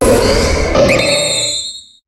Cri de Méga-Gallame dans Pokémon HOME.